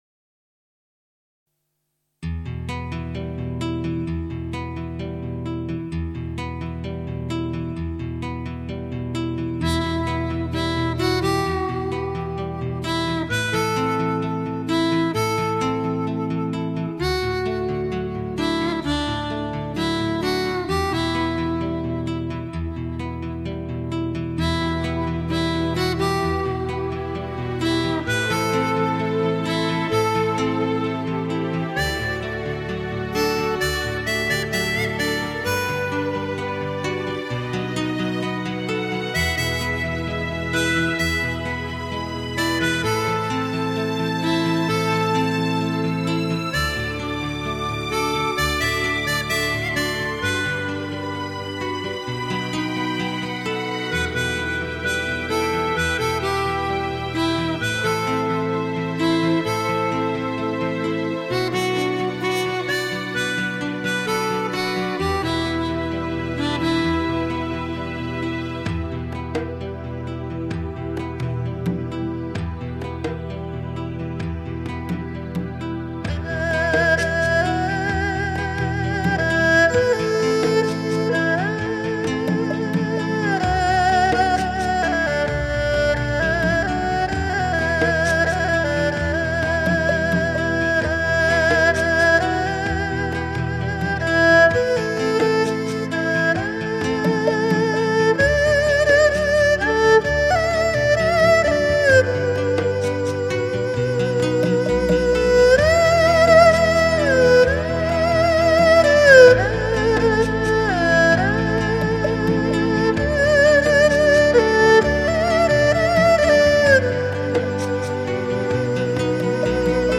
如泣如诉的琴声散发出松香味道奏出画外之音 传统的中国乐器二胡与电声乐器给人新的感受